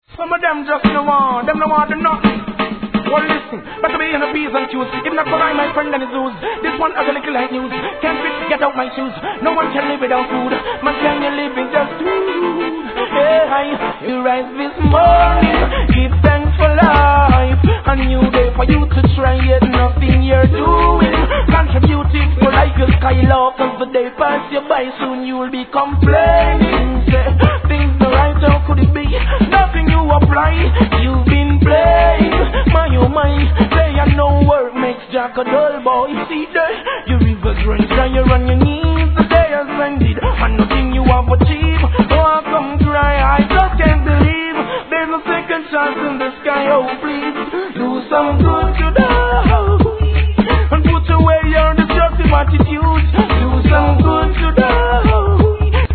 REGGAE
1999年、得意のの裏声が冴えるヒット作!